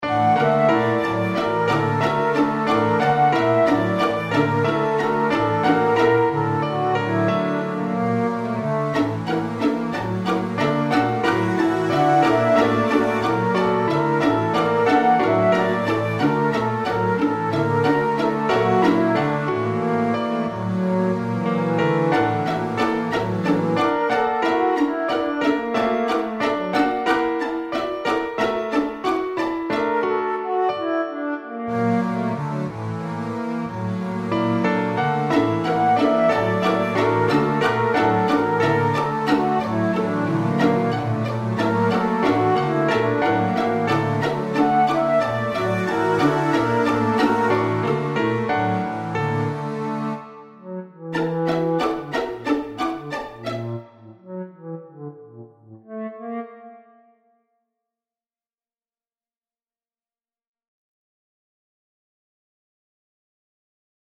Many of the songs labeled “digital” were composed using transformations of cellular automata in Mathematica.